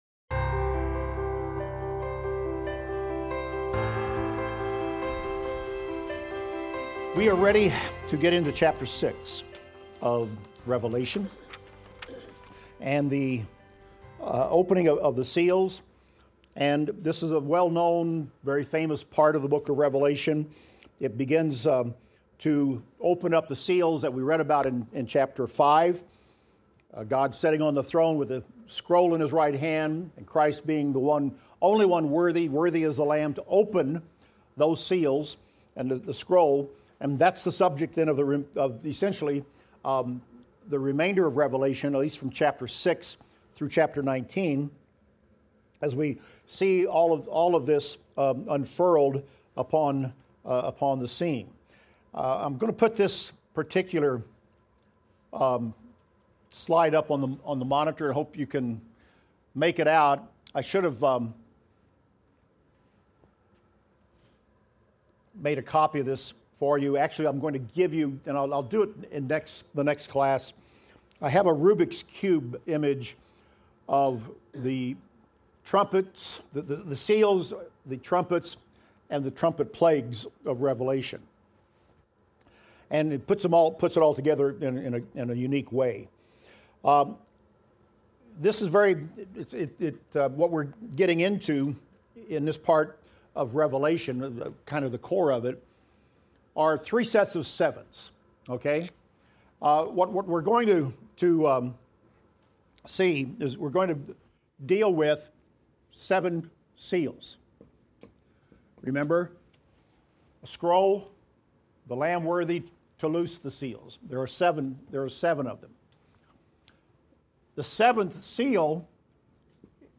Revelation - Lecture 36 - Audio.mp3